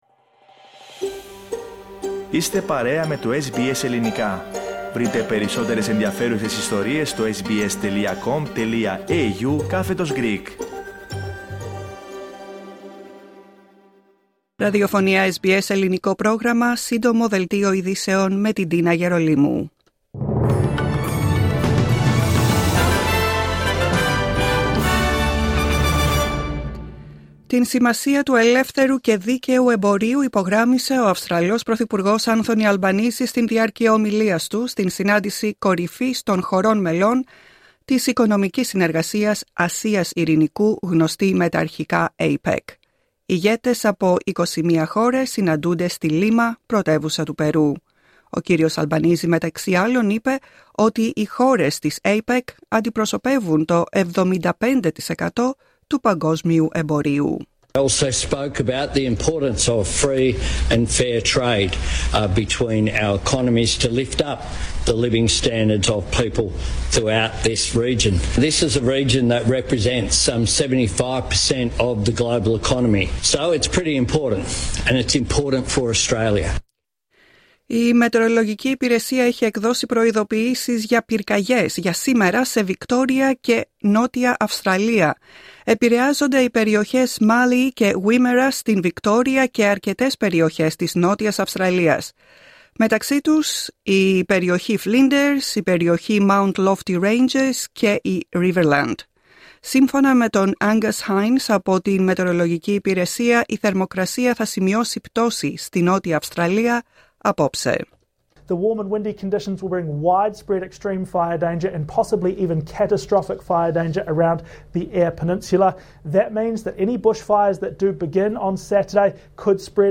Σύντομο δελτίο ειδήσεων απ΄το Ελληνικό Πρόγραμμα της SBS.